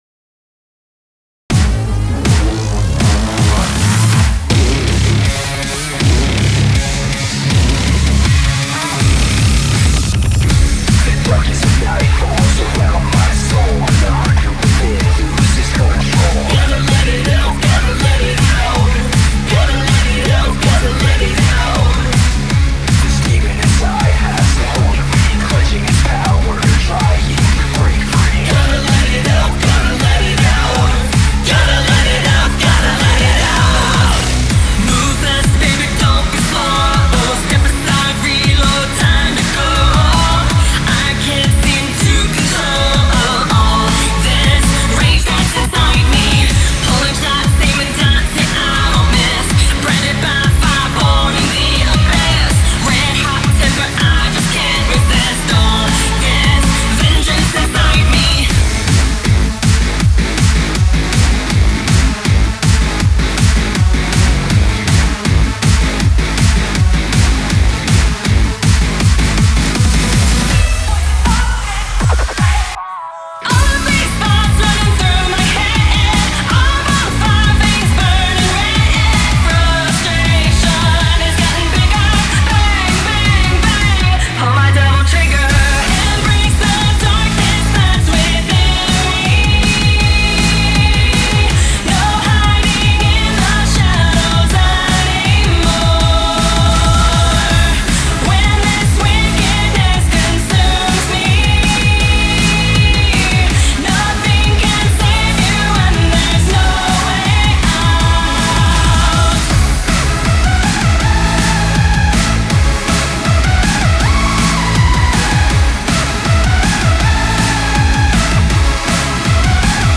BPM160
Audio QualityPerfect (High Quality)
battle theme